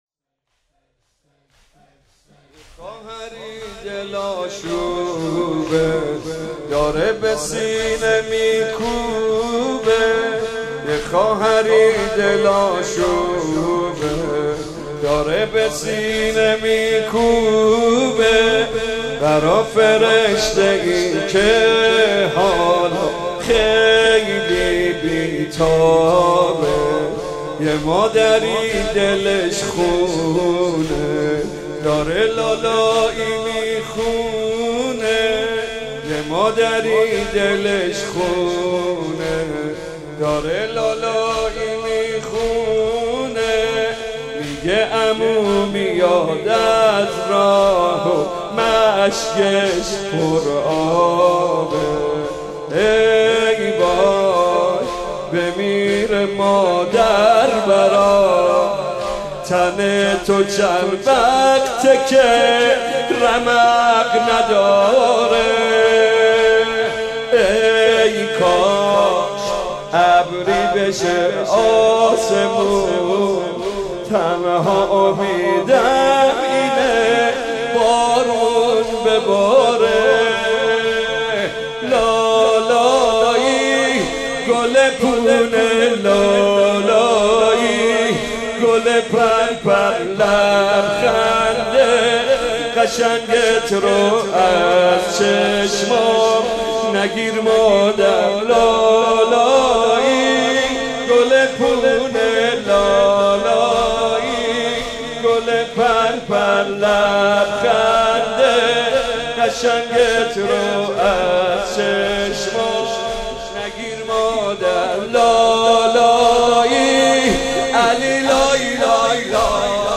مداحی
در ادامه صوت کامل مداحی مراسم شب هفتم محرم را دریافت کنید و بشنوید: